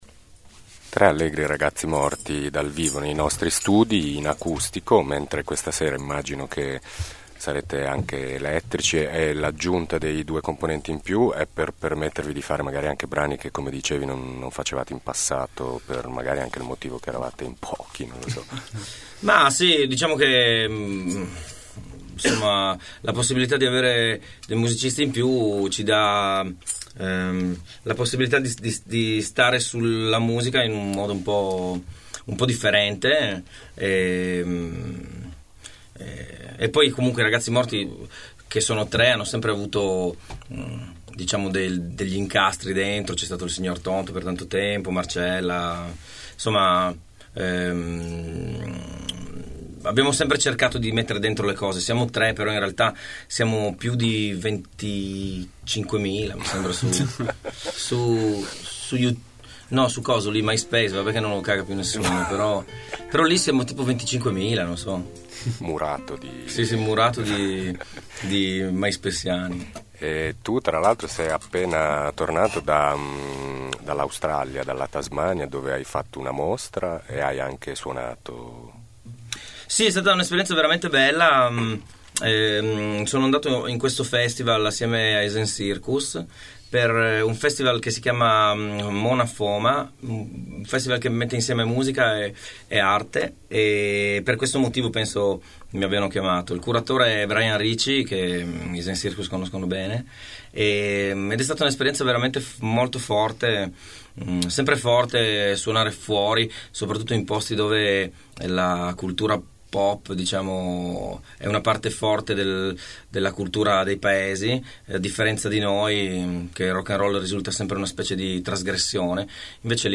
In occasione del loro concerto all’Estragon sabato scorso, sono venuti a trovarci a Thermos i Tre Allegri Ragazzi Morti.
tre-allegri-ragazzi-morti-intervista-2.mp3